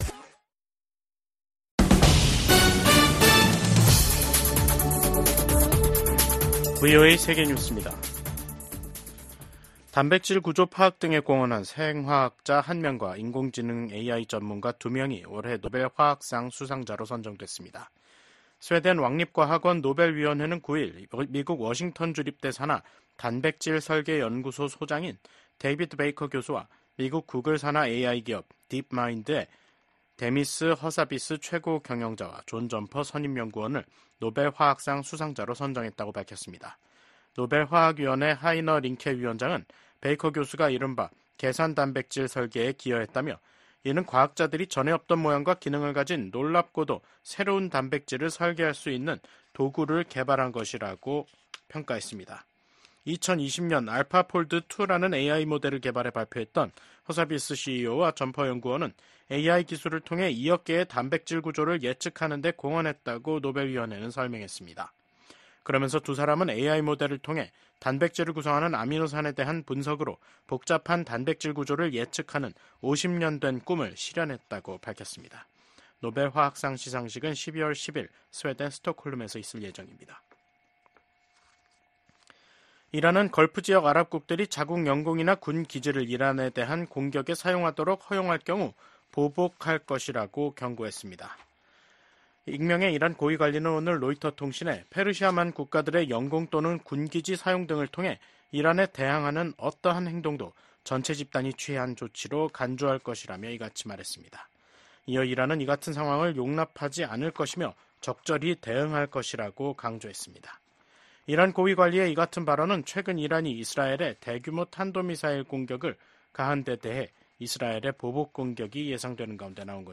VOA 한국어 간판 뉴스 프로그램 '뉴스 투데이', 2024년 10월 9일 2부 방송입니다. 북한은 한국과 연결되는 도로와 철도를 끊고 한국과의 국경을 영구 차단하는 공사를 진행한다고 선언했습니다. 군축과 국제안보를 담당하는 유엔총회 제1위원회에서 북한의 대량살상무기 개발과 북러 군사협력에 대한 규탄이 이어지고 있습니다. 북한이 우크라이나 도네츠크 지역에 인력을 파견했다는 보도와 관련해 미 국방부는 북한의 대러 지원 움직임을 주시하고 있다고 강조했습니다.